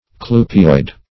Search Result for " clupeoid" : The Collaborative International Dictionary of English v.0.48: Clupeoid \Clu"pe*oid\, a. [L.clupea a kind of fish, NL., generic name of the herring + -oid.]